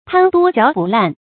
注音：ㄊㄢ ㄉㄨㄛ ㄐㄧㄠˊ ㄅㄨˋ ㄌㄢˋ